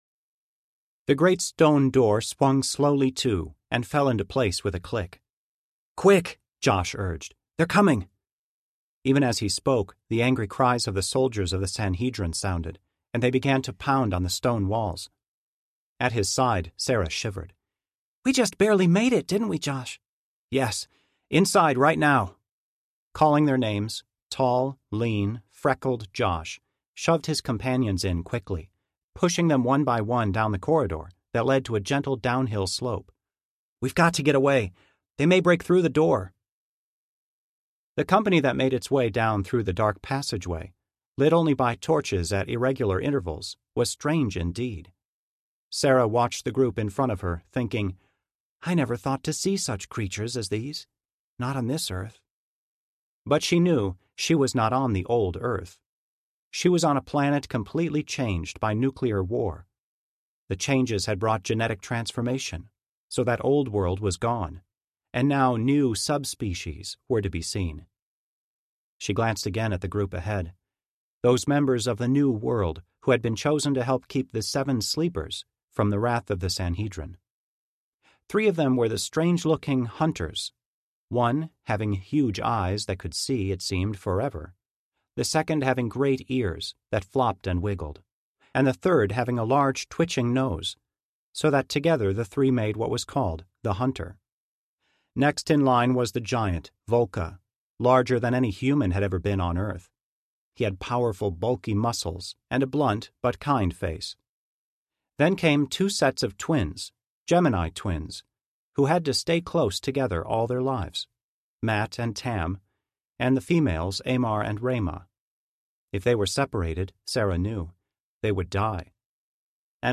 The Gates of Neptune (Seven Sleepers, Book #2) Audiobook
Narrator
4.0 Hrs. – Unabridged